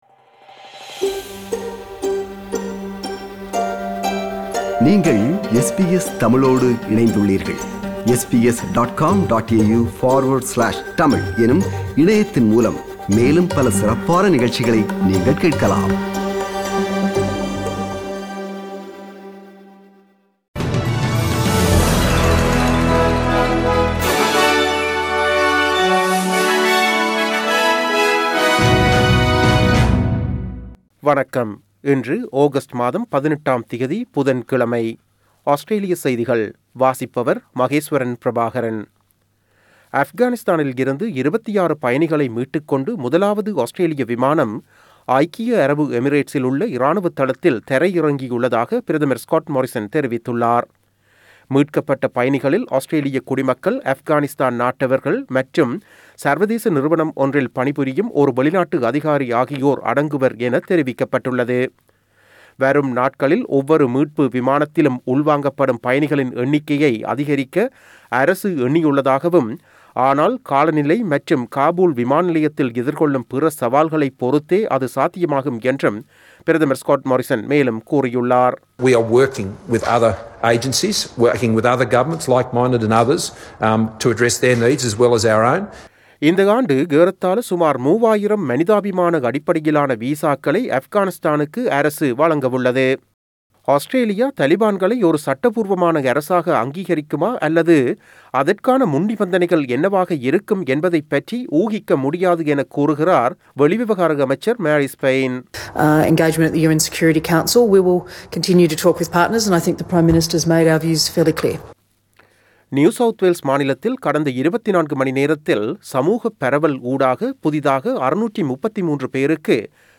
Australian news bulletin for Wednesday 18 August 2021.